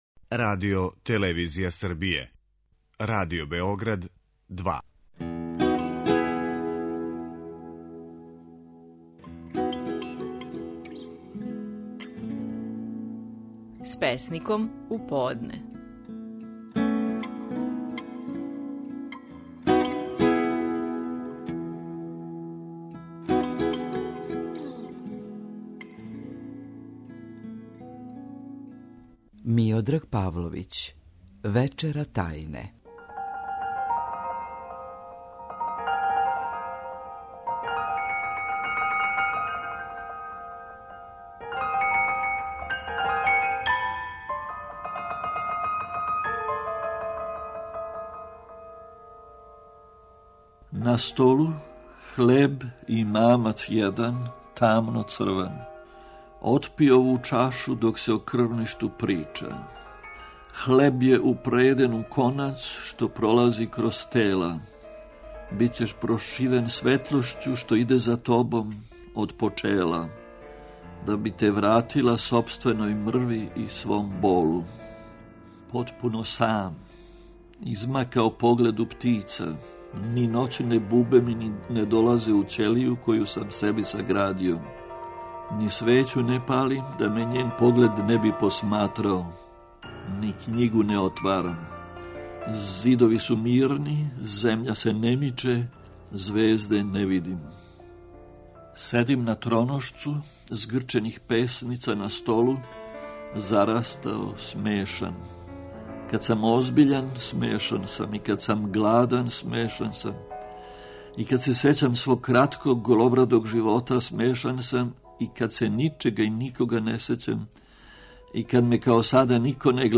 Стихови наших најпознатијих песника, у интерпретацији аутора
Миодраг Павловић говори песму „Вечера тајне".